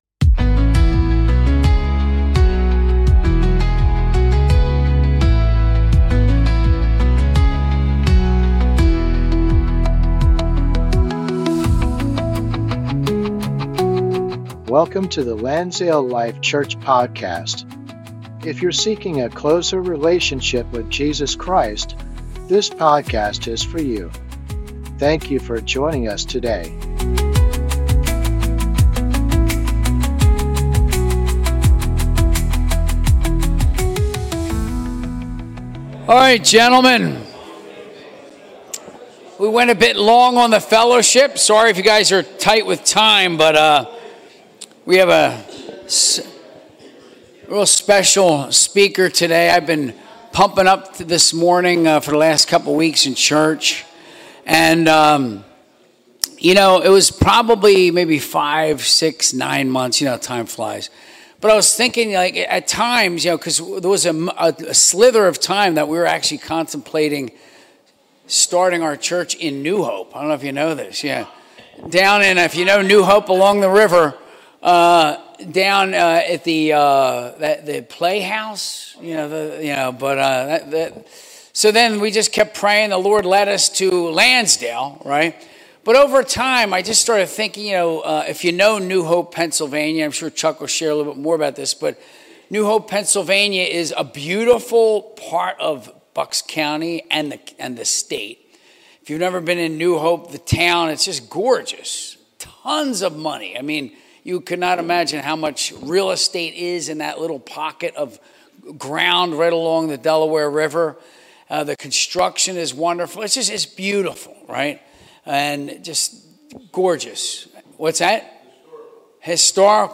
Men's Breakfast - 2025-11-29